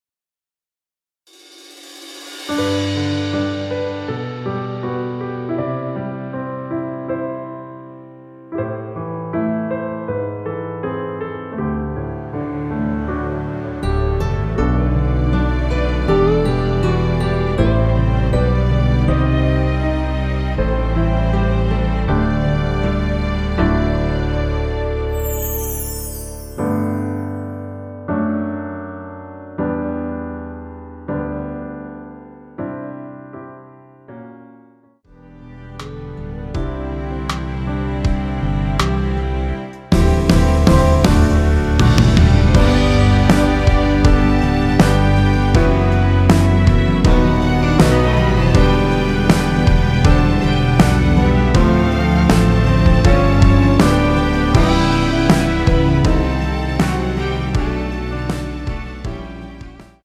원키에서(-3)내린 MR입니다.
앞부분30초, 뒷부분30초씩 편집해서 올려 드리고 있습니다.
중간에 음이 끈어지고 다시 나오는 이유는